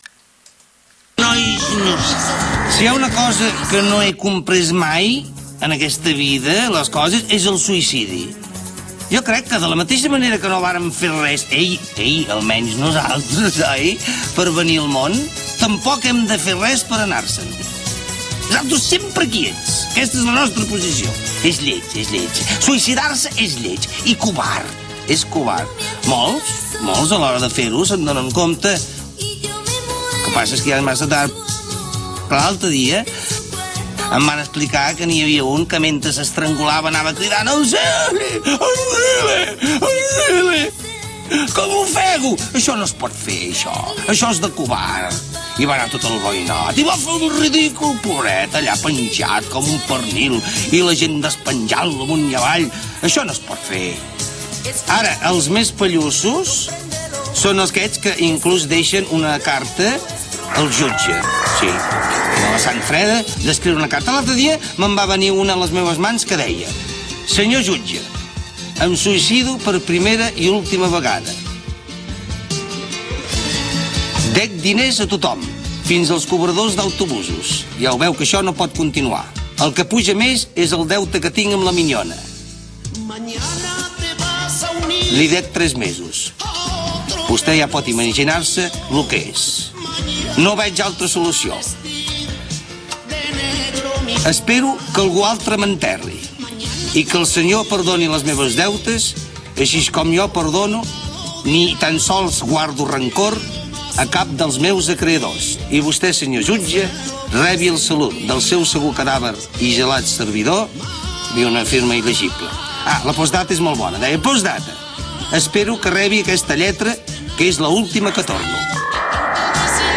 Su tono es alegre, animoso, como no podía ser menos.